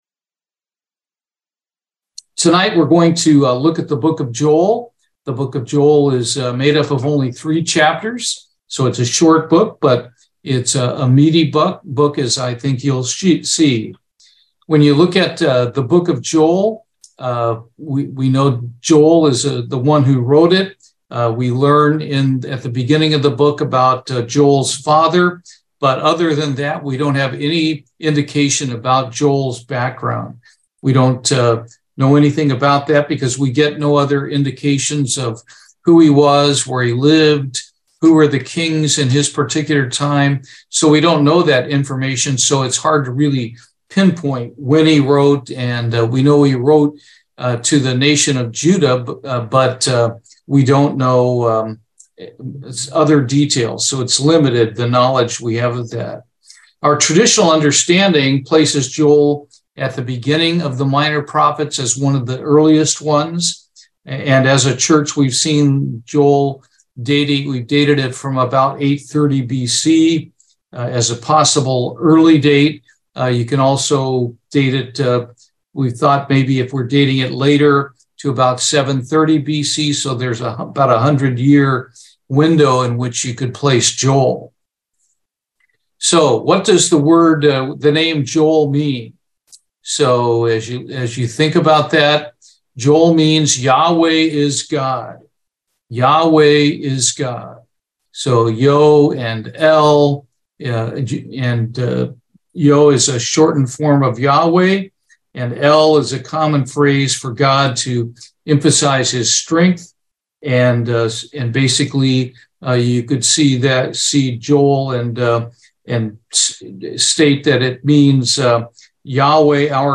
Minor Prophets Bible Study, Joel